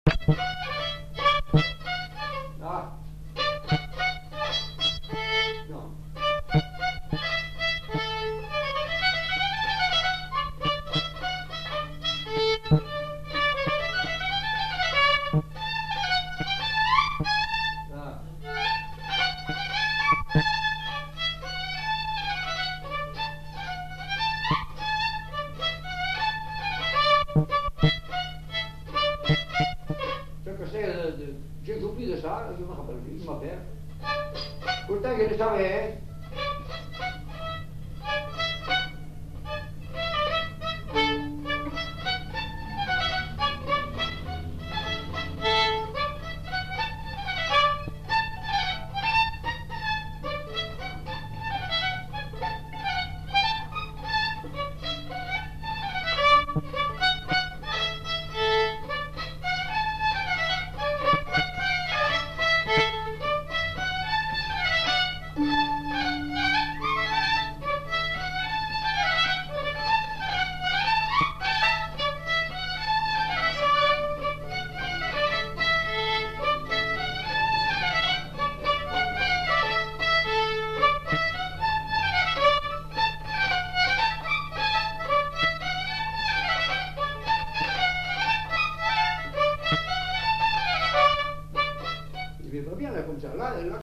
Aire culturelle : Lugues
Lieu : Casteljaloux
Genre : morceau instrumental
Instrument de musique : violon
Danse : congo
Notes consultables : 2 violons.